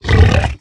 Minecraft Version Minecraft Version snapshot Latest Release | Latest Snapshot snapshot / assets / minecraft / sounds / mob / hoglin / angry6.ogg Compare With Compare With Latest Release | Latest Snapshot
angry6.ogg